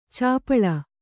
♪ cāpaḷa